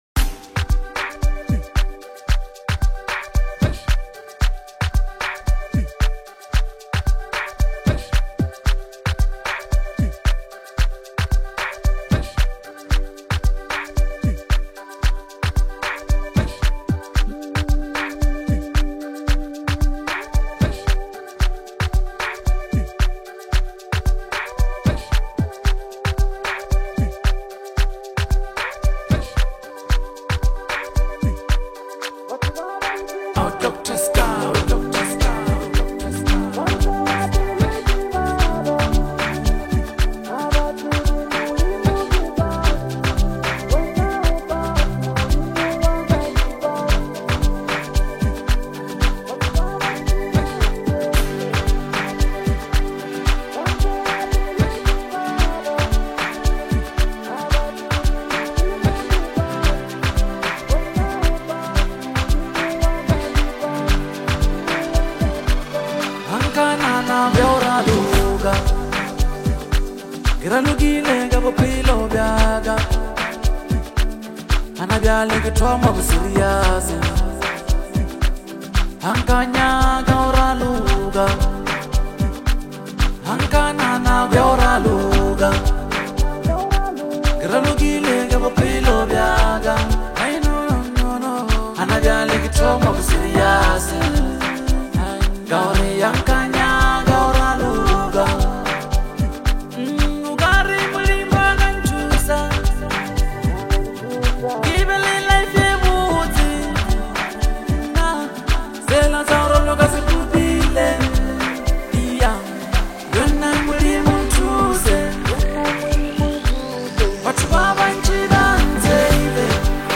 spiritually rich and rhythmically rooted